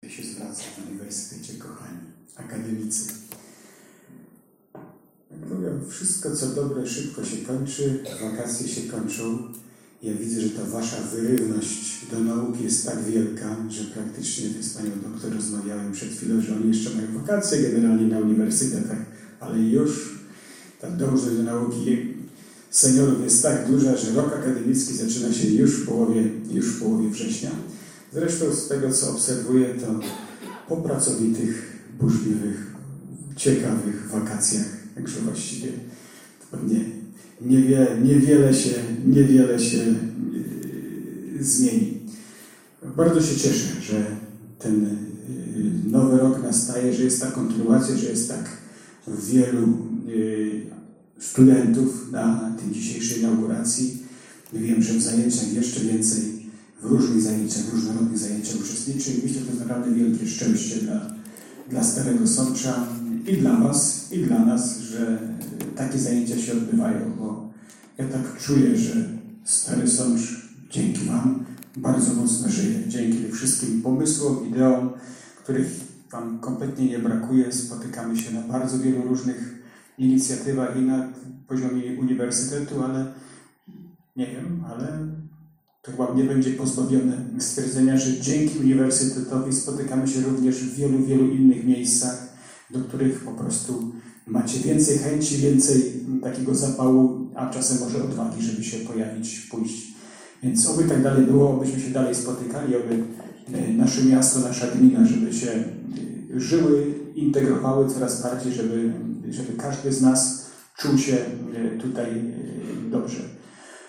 Starosądeccy seniorzy wrócili na studia
Posłuchaj burmistrza Jacka Lelka